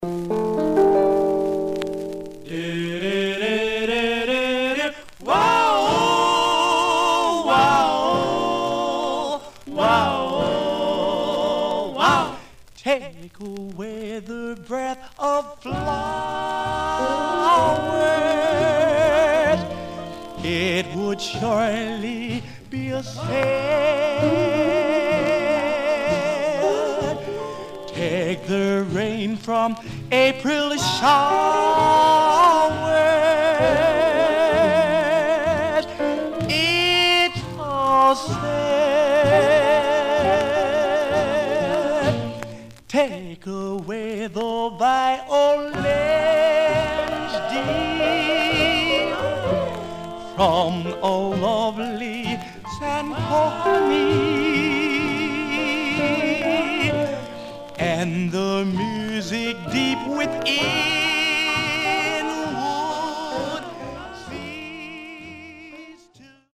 Stereo/mono Stereo
Male Black Groups